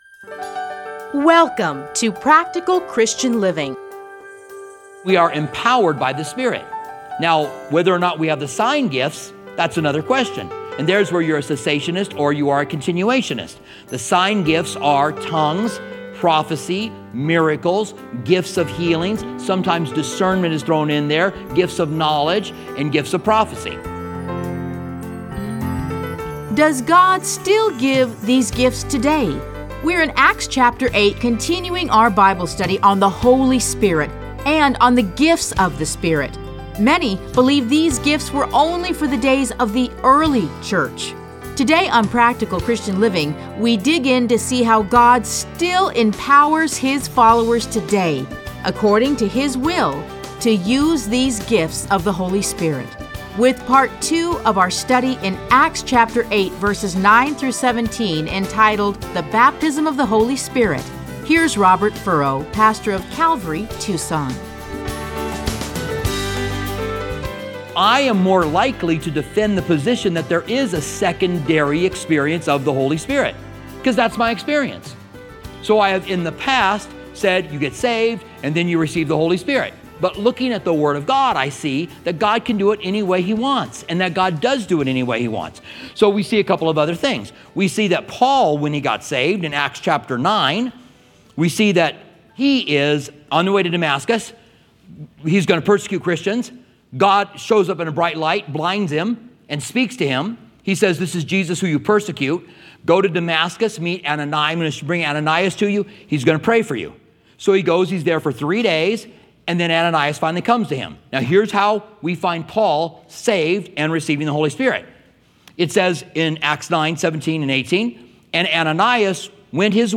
Listen to a teaching from Acts 8:9-17.